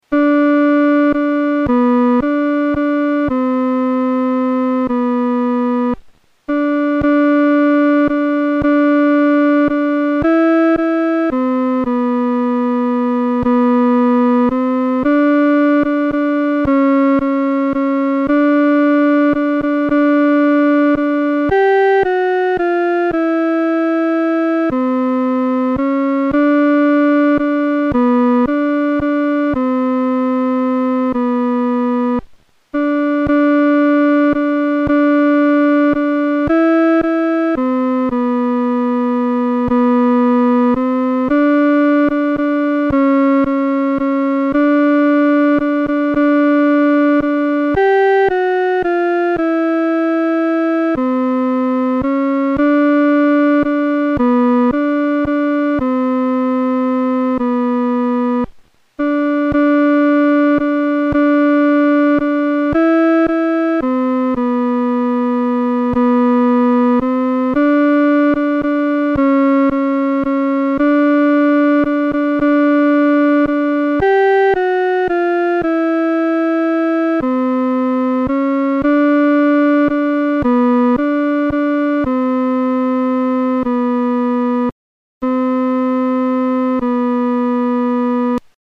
伴奏
女低
指挥在带领诗班时，表情和速度应采用温柔而缓慢地。